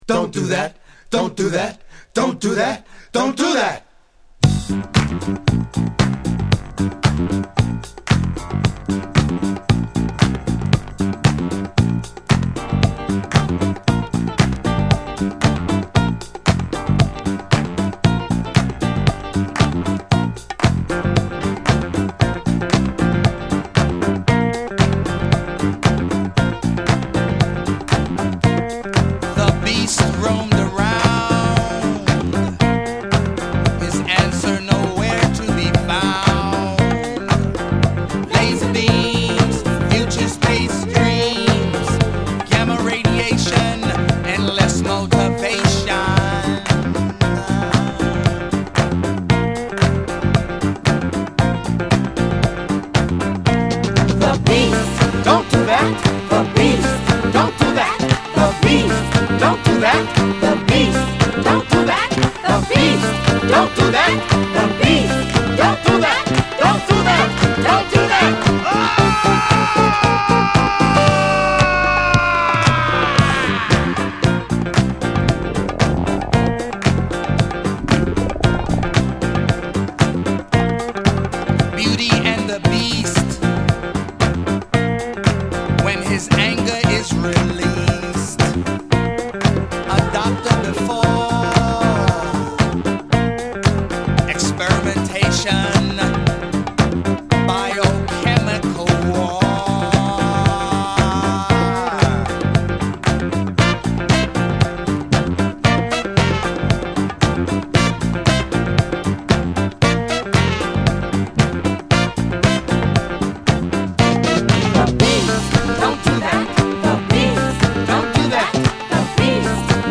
※試聴ファイルは別コピーからの録音です。